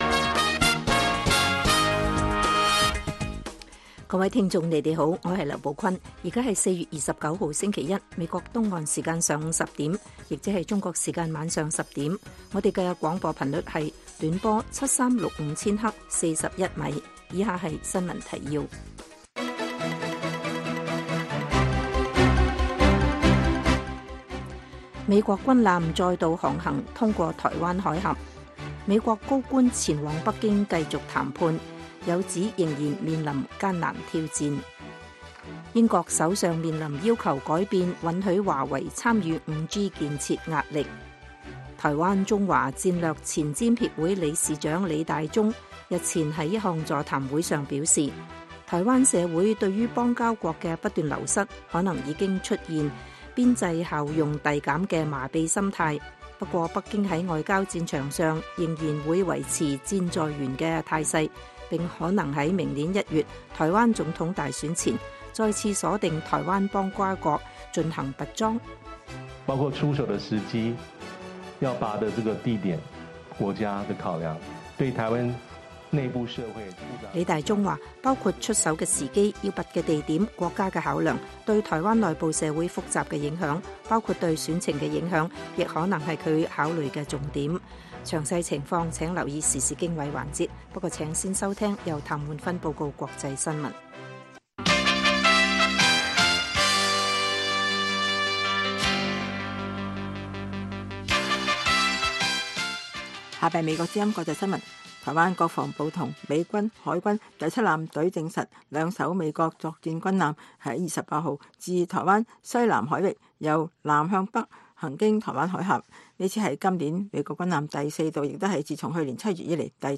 北京時間每晚10－11點 (1400-1500 UTC)粵語廣播節目。內容包括國際新聞、時事經緯、英語教學和社論。